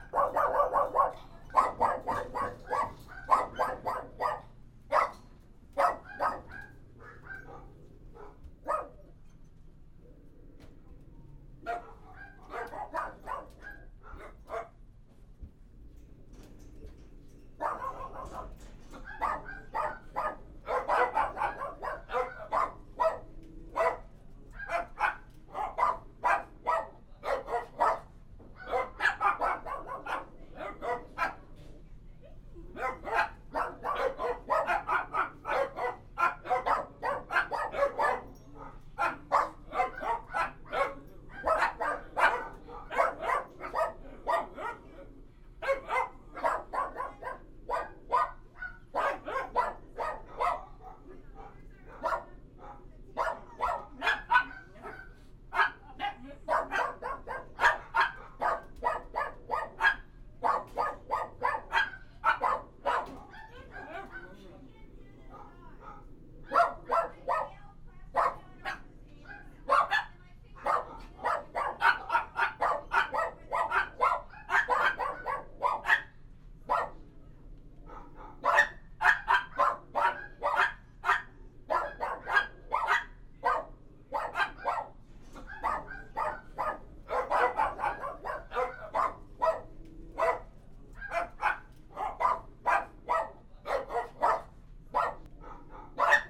dog-barking-sounds